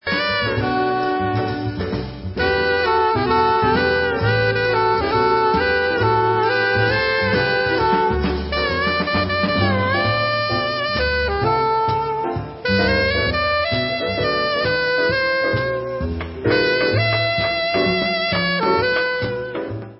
sledovat novinky v oddělení Jazz